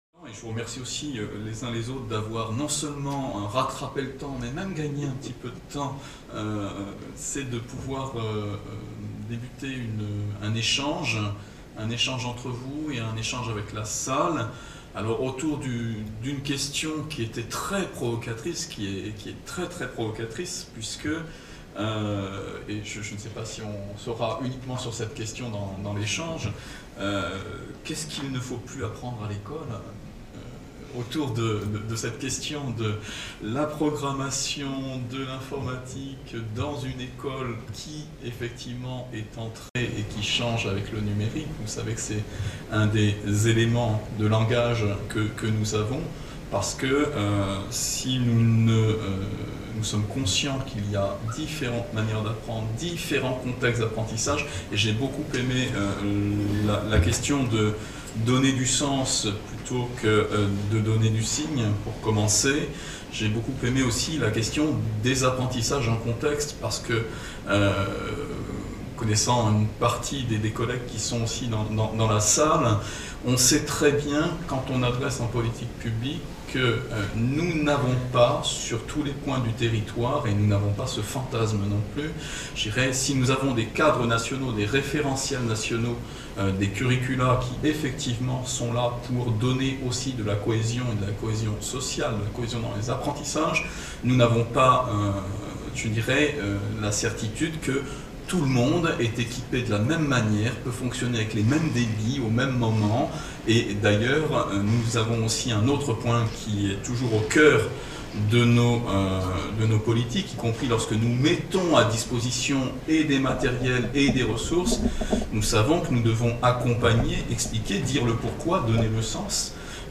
Table ronde ouverte